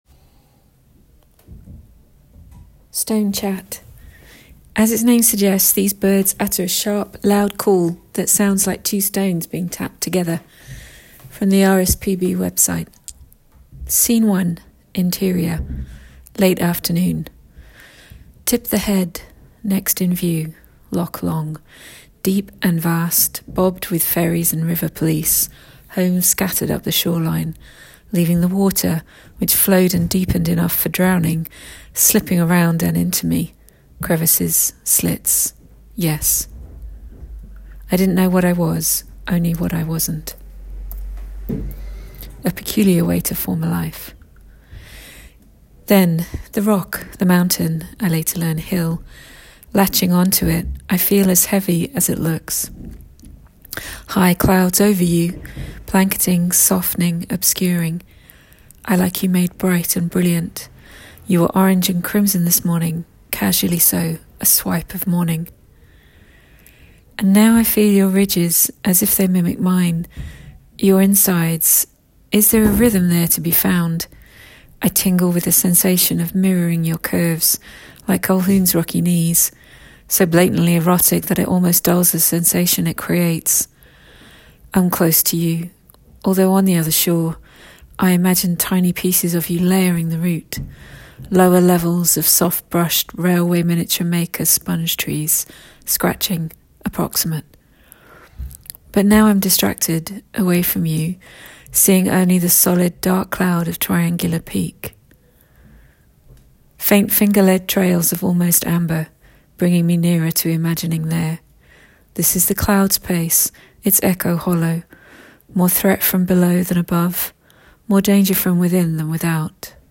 Click to play audio (recorded in studio) – of new written work in development – reflective text / imaginary dialogue.